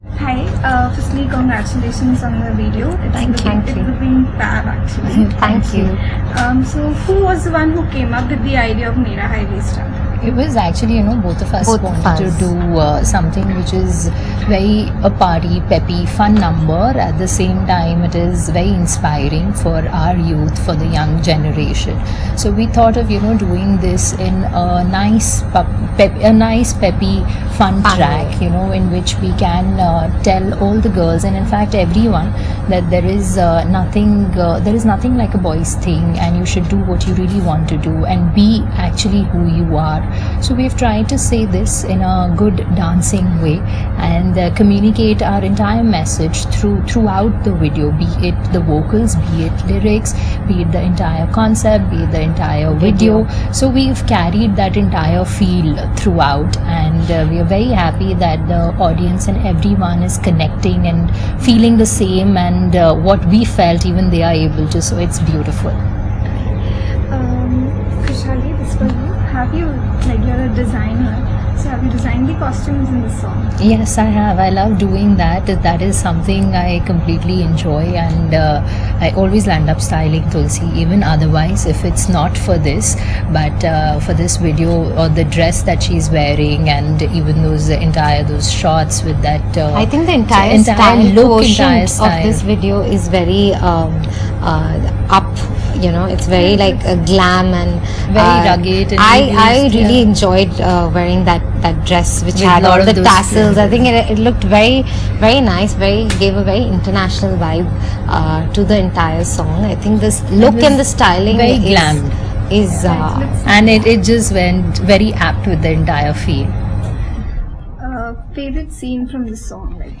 UrbanAsian got a chance to interview Tulsi and Khushali, and we got a lot to know about them, their look in the song and how they both wanna work with Honey Singh!